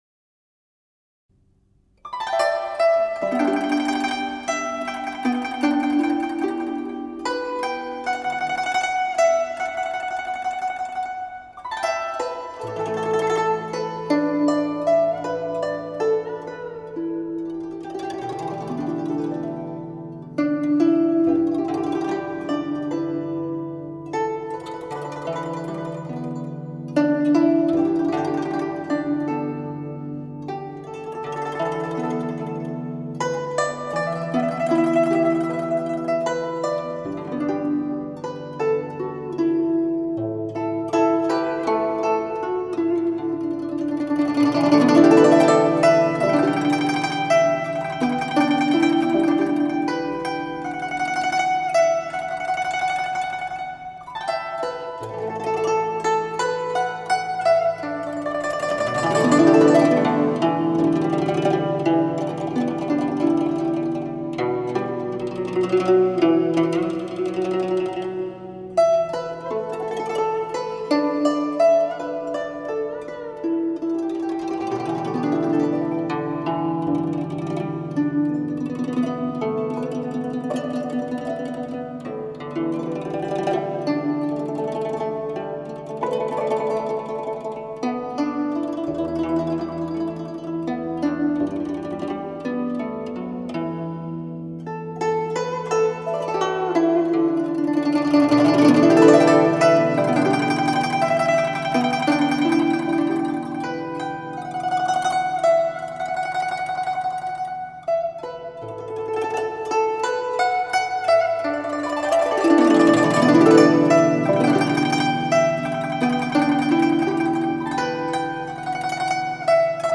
音色通透